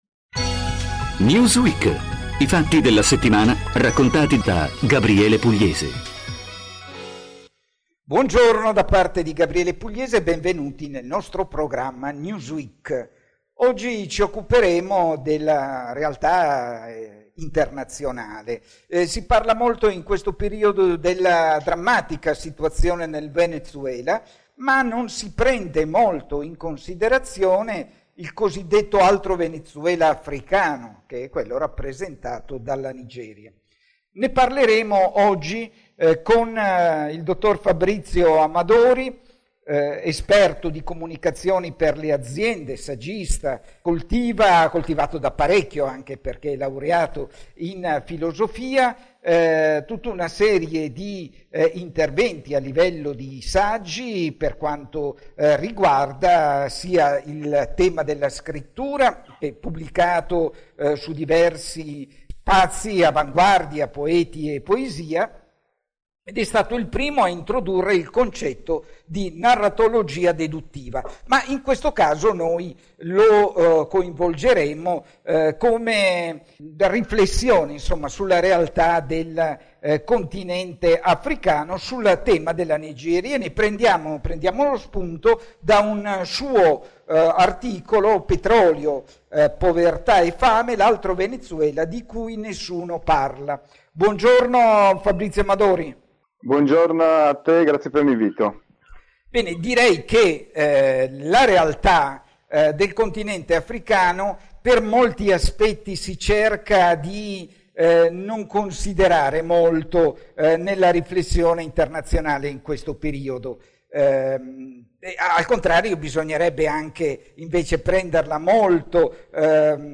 INTERVISTA A RADIO POLIS del 4/4/2019 SULLA QUESTIONE DEL NEOCOLONIALISMO OCCIDENTALE IN AFRICA